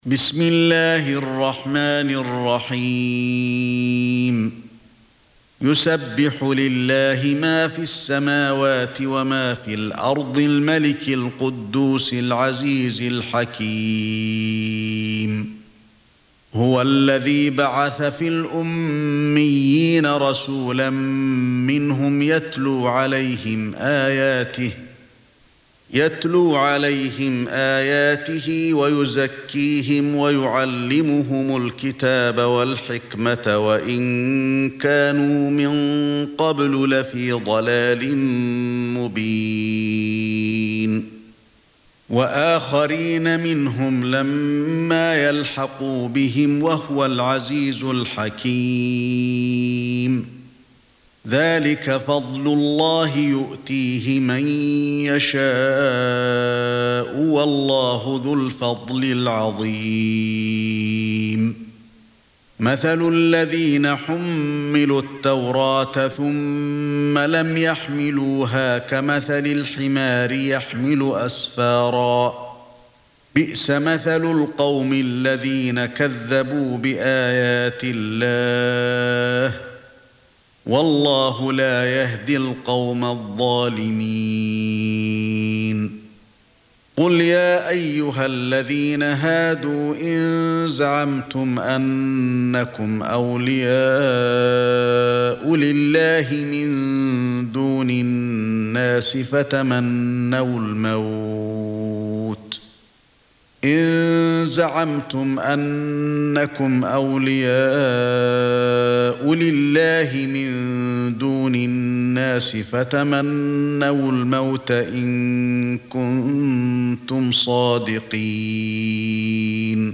62. Surah Al-Jumu'ah سورة الجمعة Audio Quran Tarteel Recitation
Surah Sequence تتابع السورة Download Surah حمّل السورة Reciting Murattalah Audio for 62. Surah Al-Jumu'ah سورة الجمعة N.B *Surah Includes Al-Basmalah Reciters Sequents تتابع التلاوات Reciters Repeats تكرار التلاوات